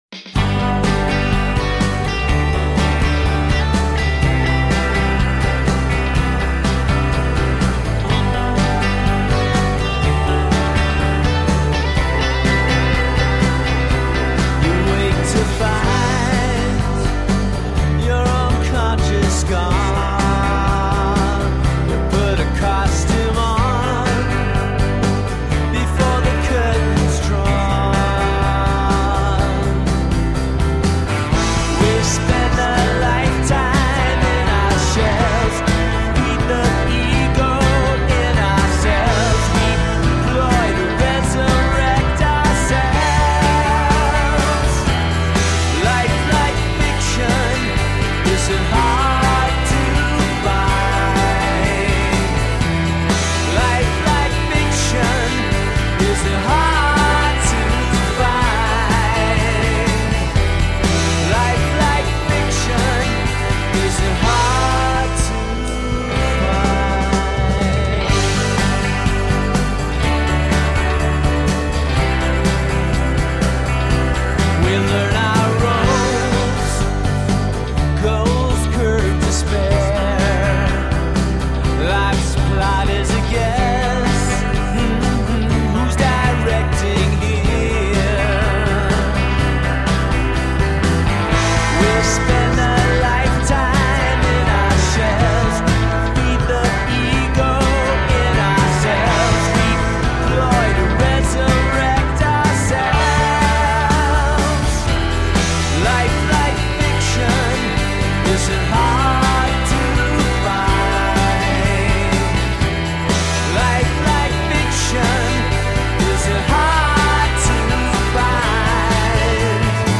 Amplified introspection powered by a single voice.
Tagged as: Alt Rock, Folk-Rock, Pop